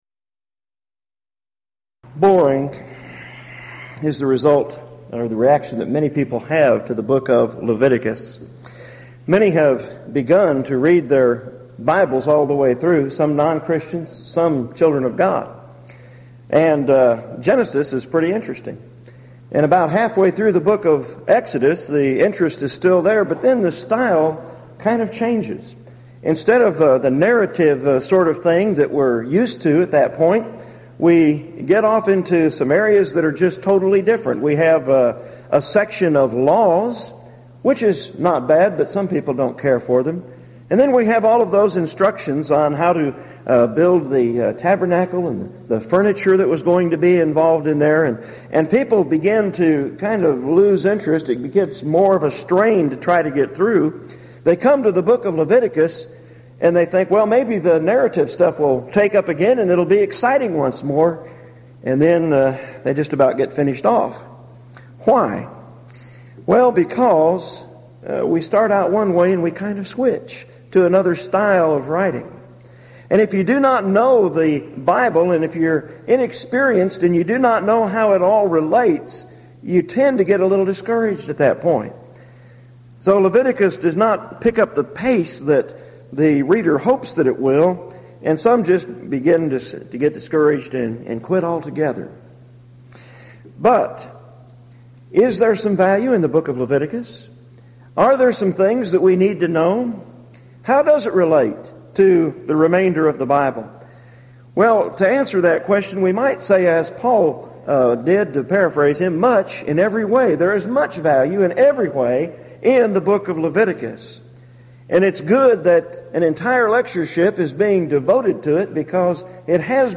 Event: 1998 East Tennessee School of Preaching Lectures Theme/Title: Studies in the Book of Leviticus
lecture